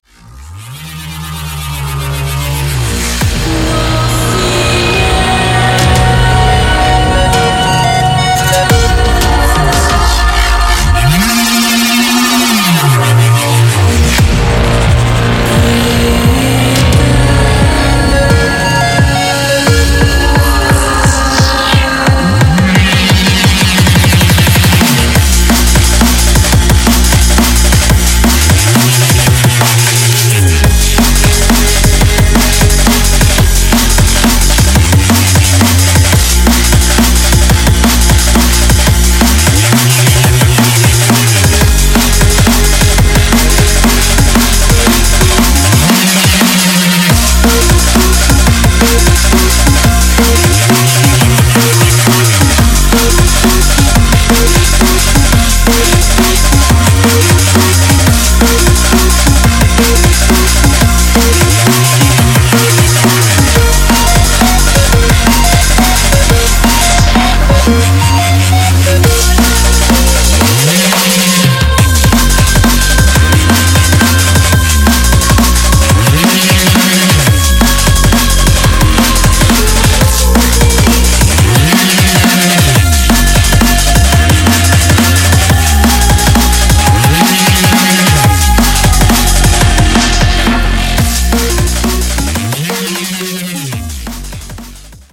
громкие
жесткие
атмосферные
Electronica
neurofunk
Стиль: drum&bass